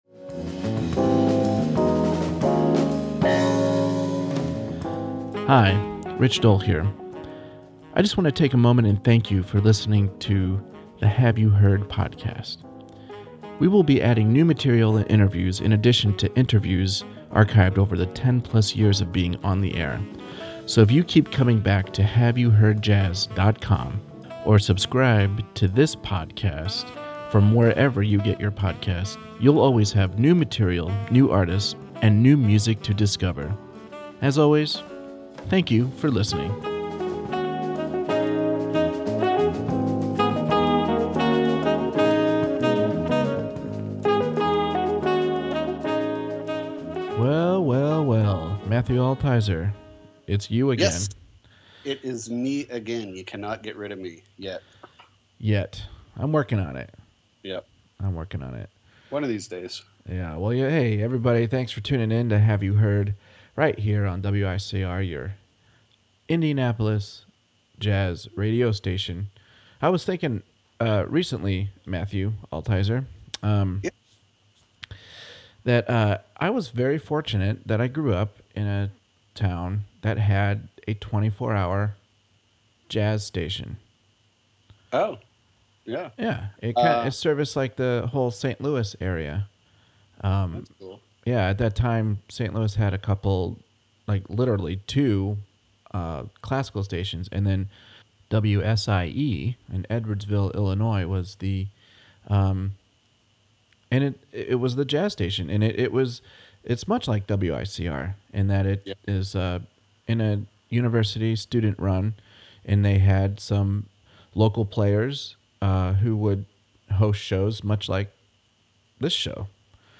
Fender Rhodes electric piano
trumpet
tenor saxophone and bass clarinet
guitar
electric bass
drums